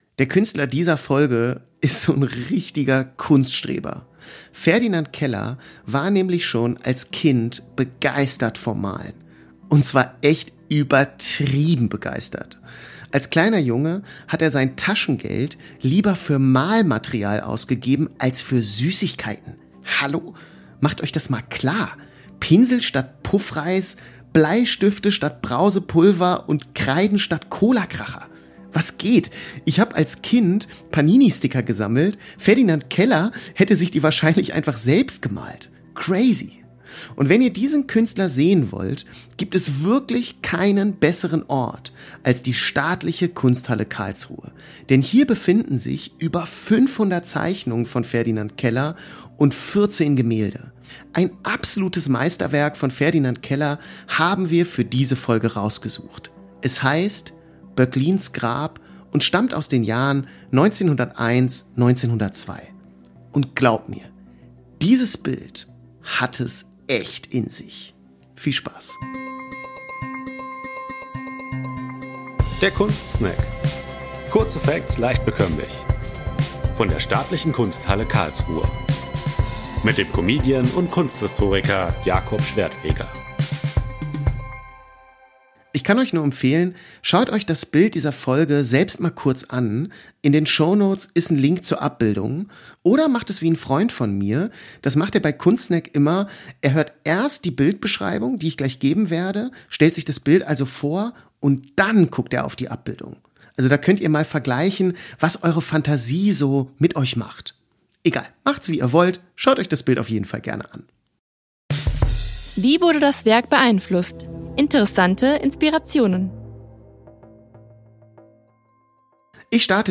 Sprecher Intro und Outro
Sprecherin der Rubriken